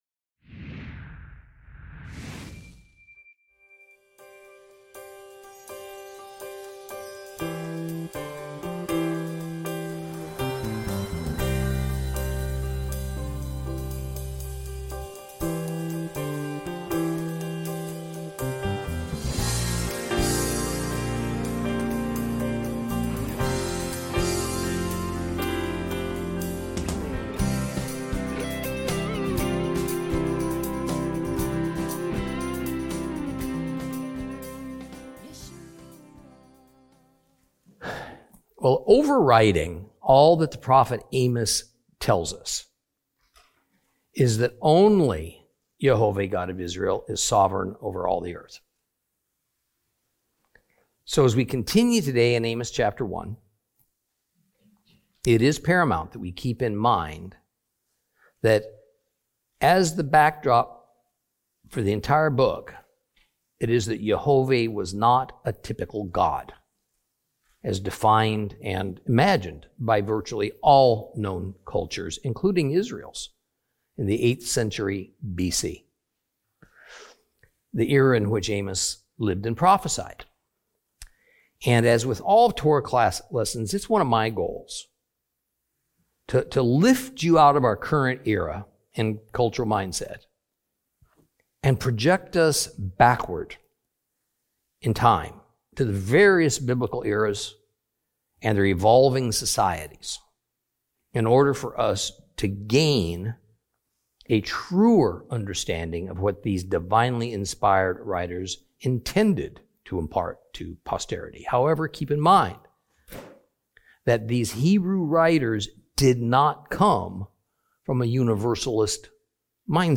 Teaching from the book of Amos, Lesson 2 Chapter 1 continued.